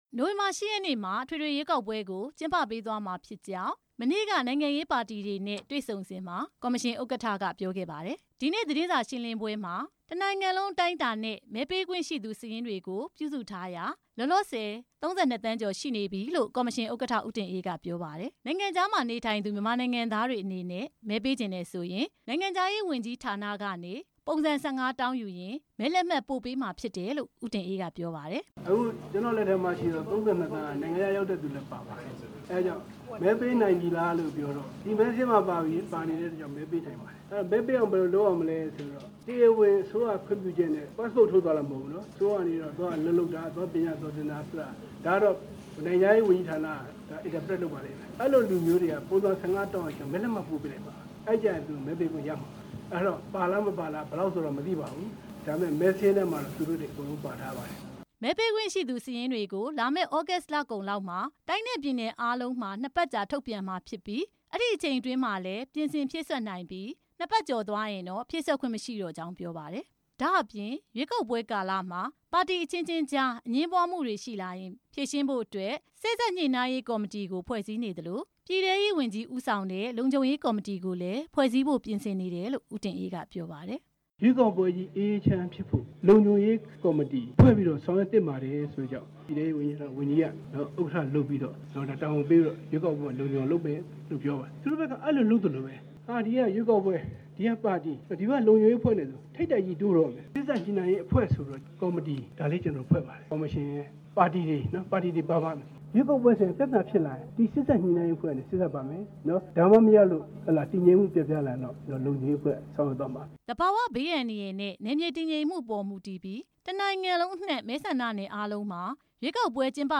ရန်ကုန်မြို့ ရွှေလီလမ်းက ရွေးကောက်ပွဲကော်မရှင်သတင်းအချက်အလက်ဌာနမှာ ဒီနေ့ ကျင်းပတဲ့ သတင်းစာရှင်းလင်းပွဲ မှာ ကော်မရှင် ဥက္ကဌ ဦးတင်အေးက ပြောခဲ့တာပါ။